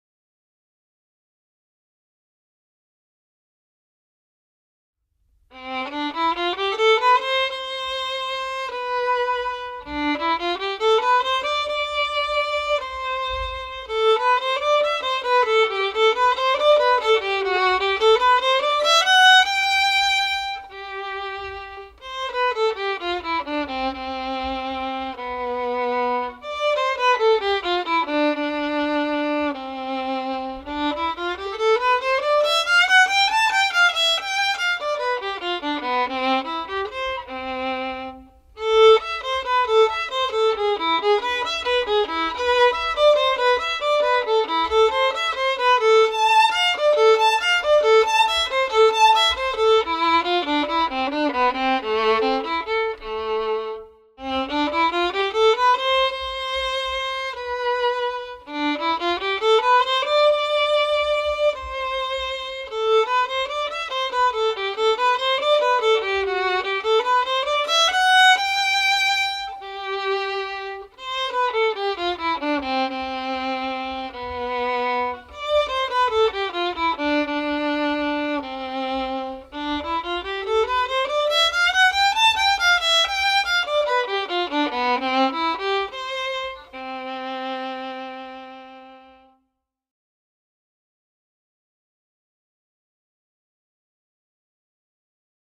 edufiddle-ESTUDIO-para-violin-No-2-Op.-84-CHARLES-DANCLA.mp3